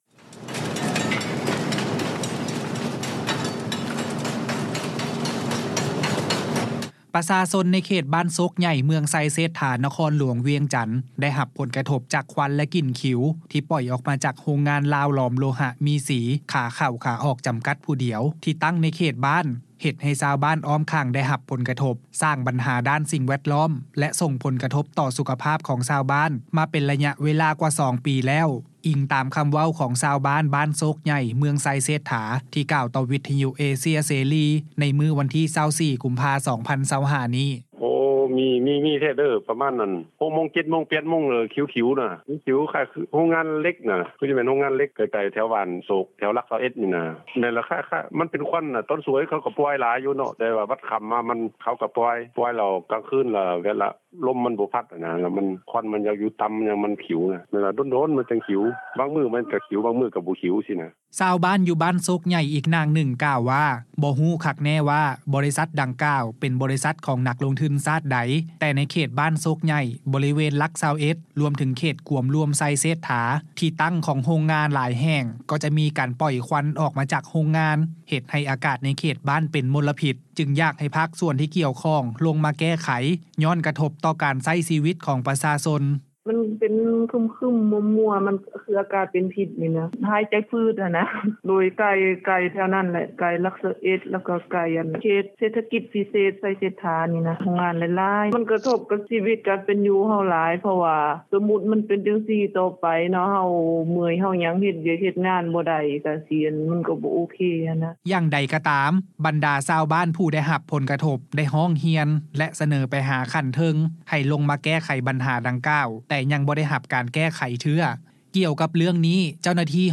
ຊາວບ້ານ ຢູ່ບ້ານໂຊກໃຫຍ່ ອີກນາງໜຶ່ງ ກ່າວວ່າ ບໍ່ຮູ້ຄັກແນ່ ວ່າ ບໍລິສັດດັ່ງກ່າວ ເປັນບໍລິສັດ ຂອງນັກລົງທຶນຊາດໃດ ແຕ່ໃນເຂດບ້ານໂຊກໃຫຍ່ ບໍລິເວນ ຫຼັກ 21 ລວມເຖິງ ເຂດກວມລວມໄຊເສດຖາ ທີ່ຕັ້ງຂອງໂຮງງານຫຼາຍແຫ່ງ ກໍຈະມີການປ່ອຍຄວັນ ອອກຈາກໂຮງງານ ເຮັດໃຫ້ອາກາດໃນເຂດບ້ານ ເປັນມົນລະພິດ ຈຶ່ງຢາກໃຫ້ພາກສ່ວນທີ່ກ່ຽວຂ້ອງ ລົງມາແກ້ໄຂ ຍ້ອນກະທົບ ຕໍ່ການໃຊ້ຊີວິດຂອງປະຊາຊົນ: